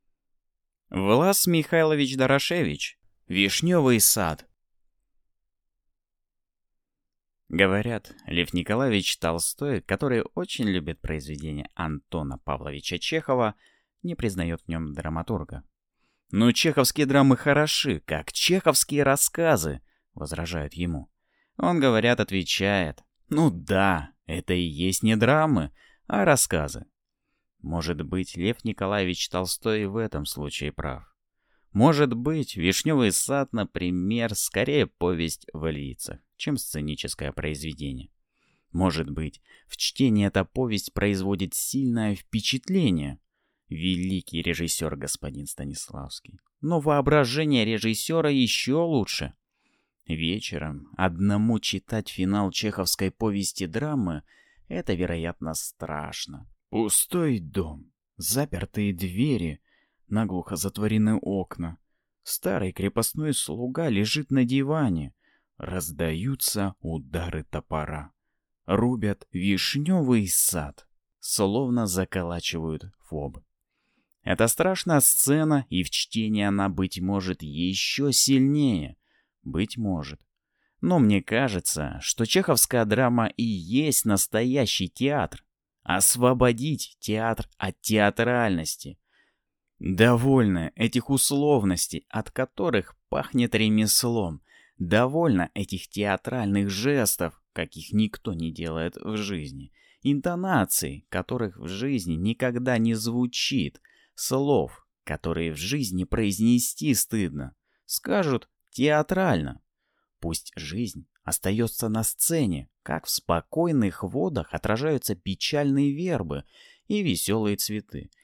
Аудиокнига «Вишневый сад» | Библиотека аудиокниг